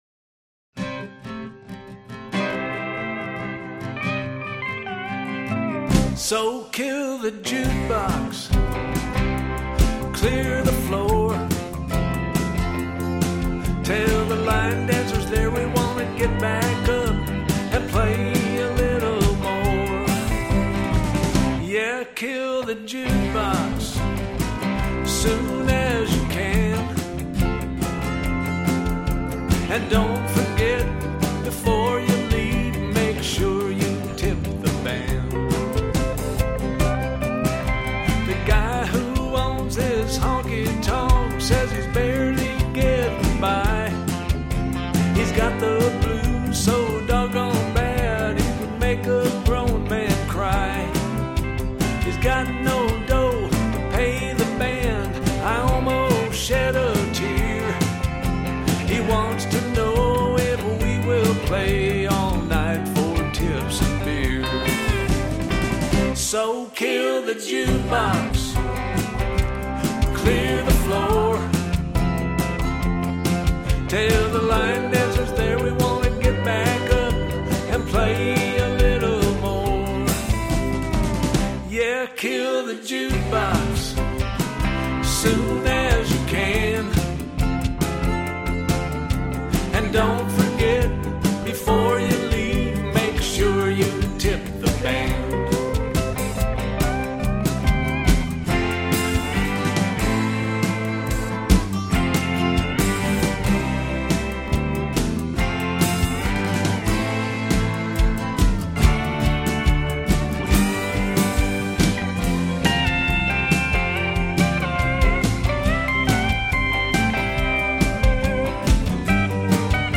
singer and acoustic guitar
Tele Guitars and Pedal Steel